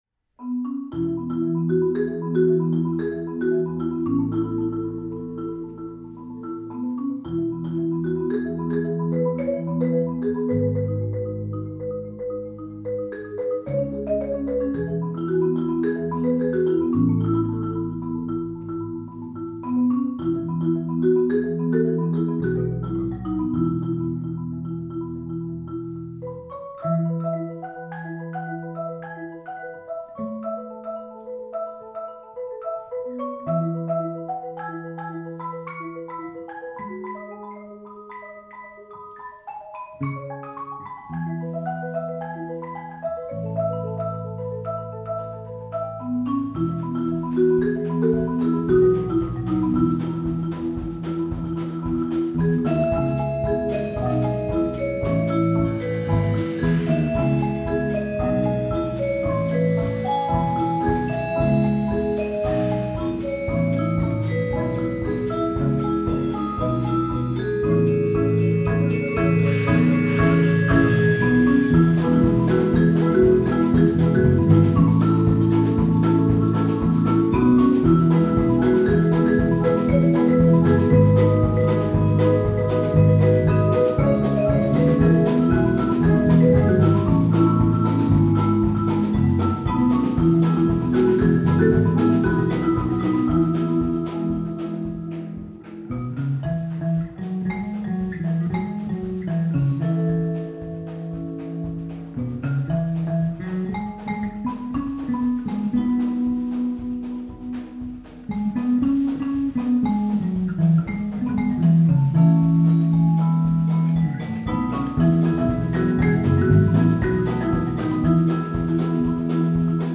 Its simple melody encapsulates the folksy sound of Americana
• Personnel: 8-10 players